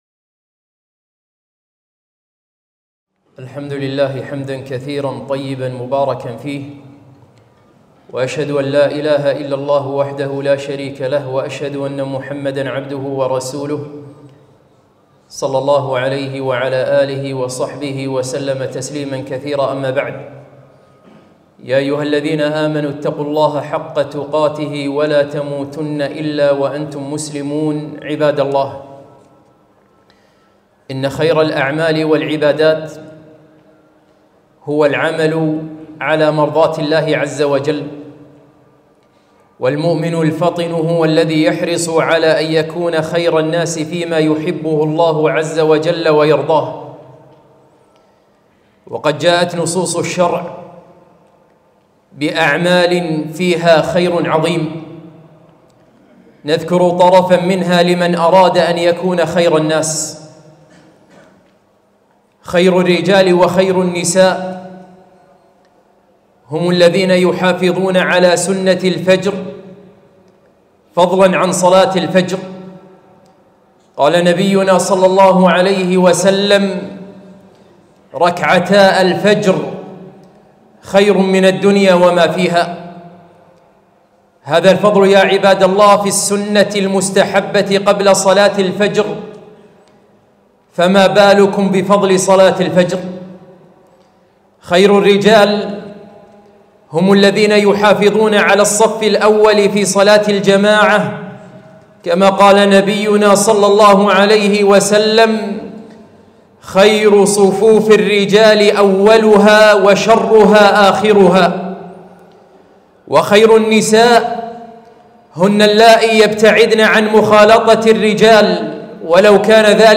خطبة - خير الرجال والنساء